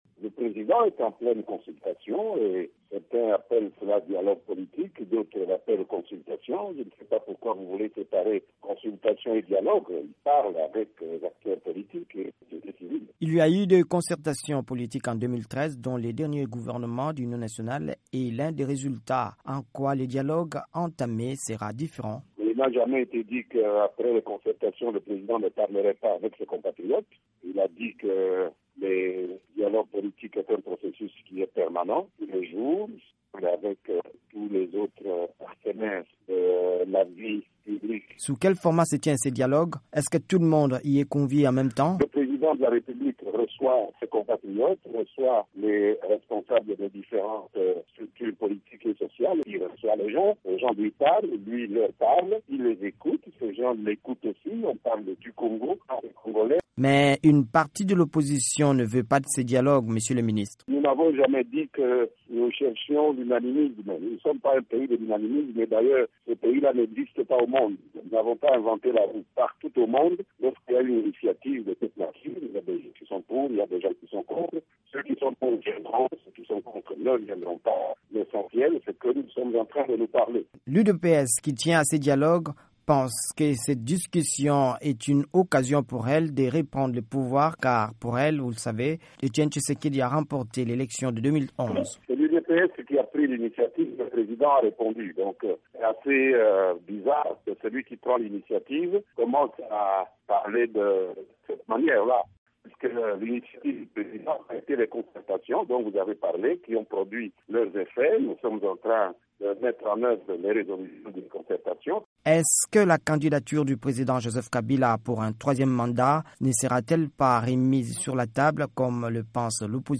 VOA Afrique a posé la question au porte-parole du gouvernement congolais, Lambert Mende.